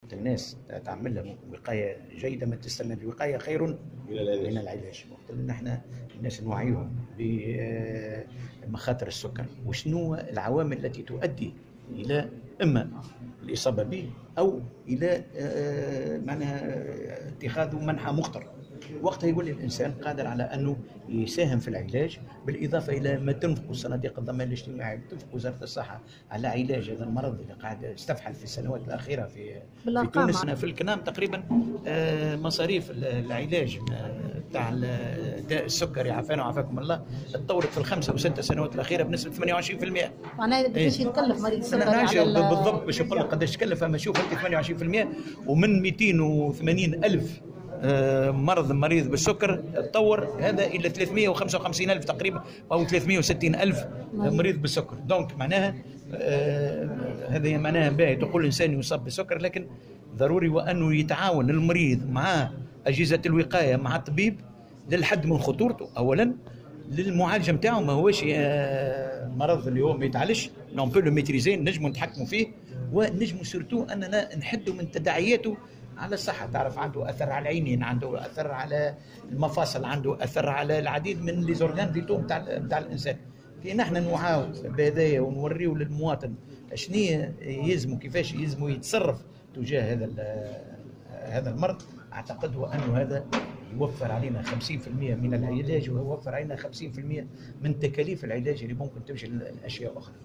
وقال في تصريح لمراسلة "الجوهرة اف أم" ان نفقات تكفل الكنام بمصاريف العلاج زادت بـ 28 % مشيرا إلى ان عدد المرضى ارتفع من 280 ألف إلى 360 ألف مريض. وجاءت تصريحاته على هامش افتتاحه دار السكري بجربة، لتكون الاولى من نوعها بالجنوب الشرقي والثالثة في تونس.